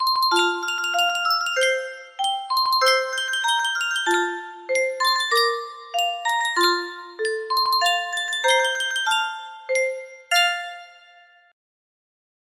Sankyo Music Box - There's a Hole in the Bottom of the Sea KVD music box melody
Full range 60